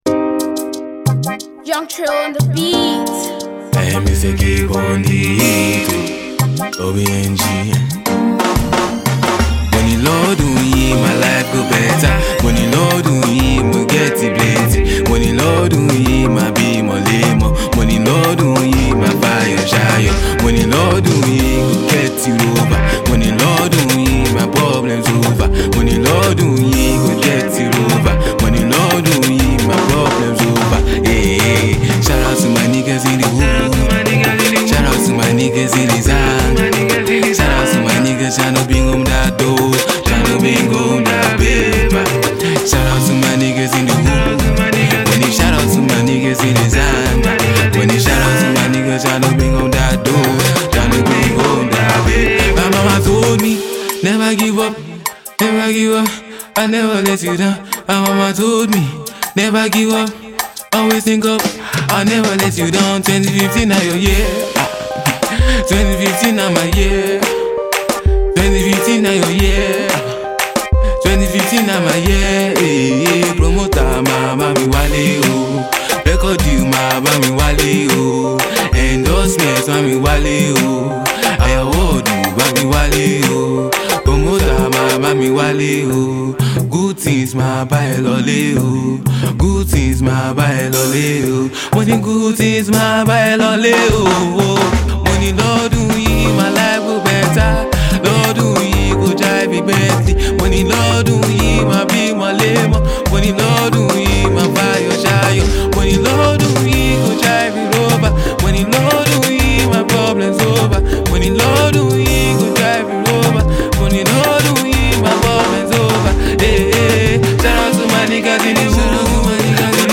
motivational track